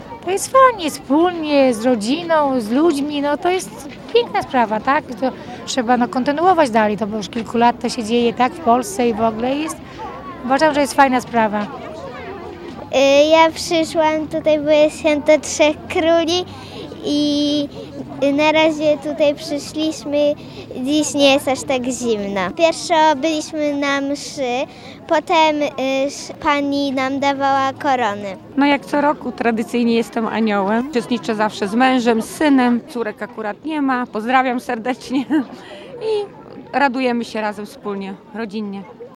Wszystkiemu towarzyszyły kolędy i radosna atmosfera.
Sonda.mp3